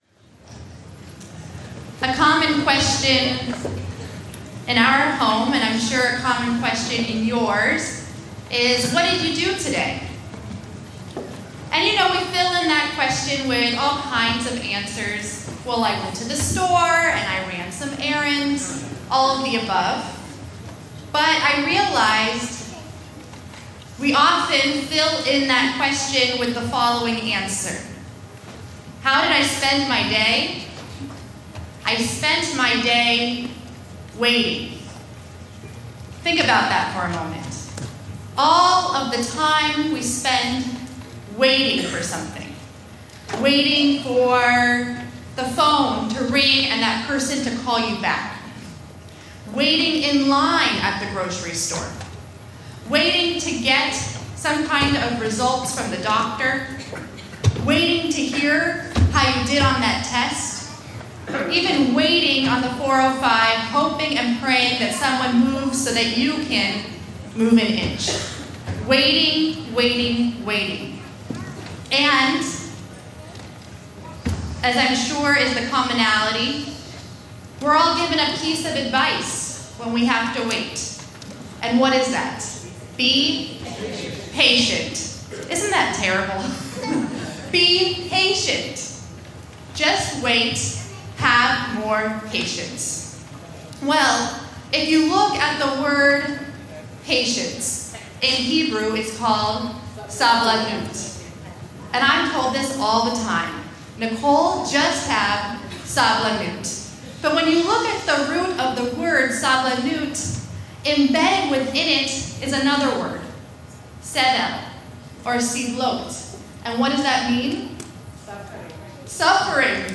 Drash